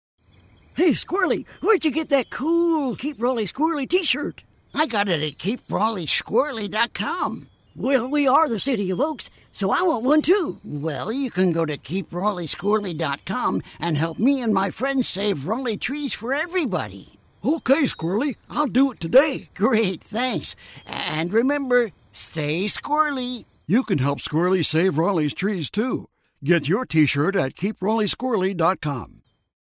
Keep Raleigh Squirrely ad to run on That Station 95.7